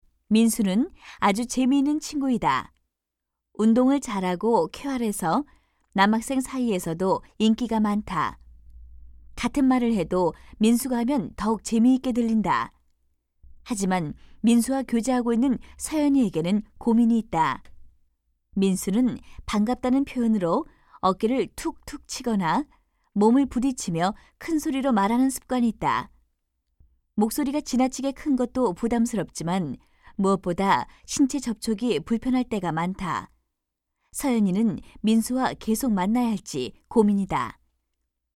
119쪽-내레이션.mp3